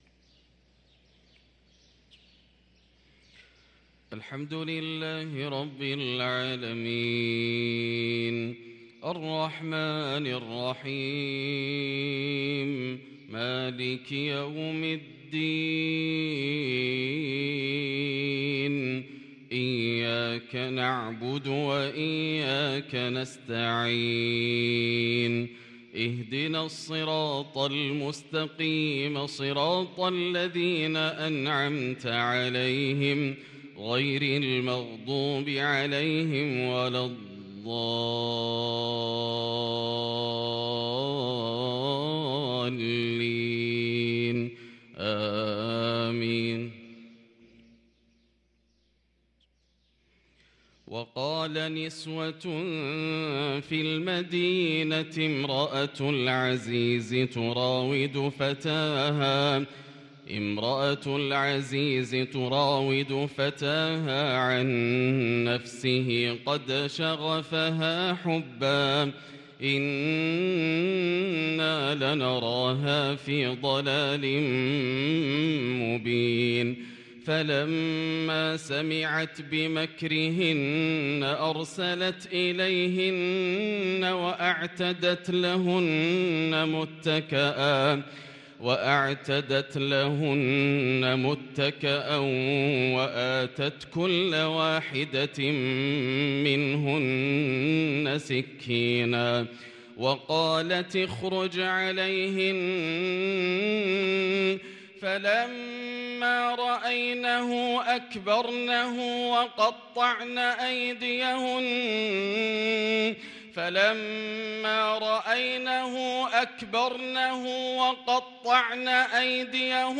صلاة الفجر للقارئ ياسر الدوسري 5 جمادي الأول 1444 هـ
تِلَاوَات الْحَرَمَيْن .